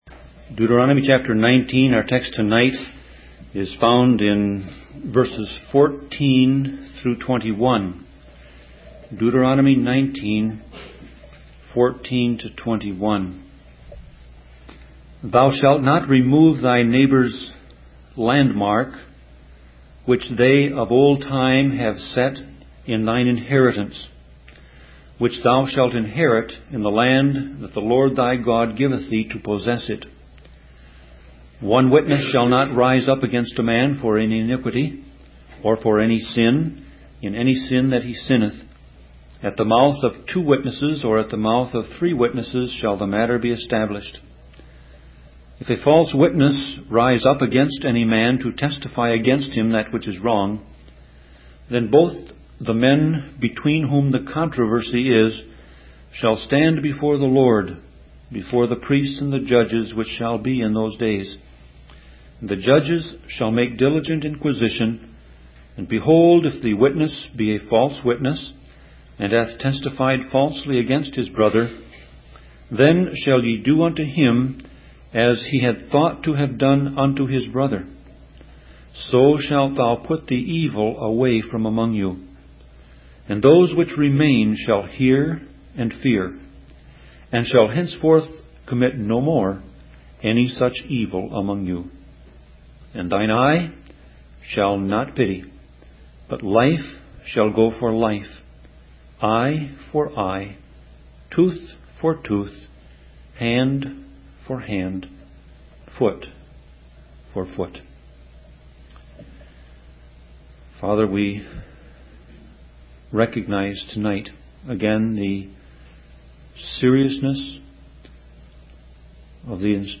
Sermon Audio Passage: Deuteronomy 19:14-21 Service Type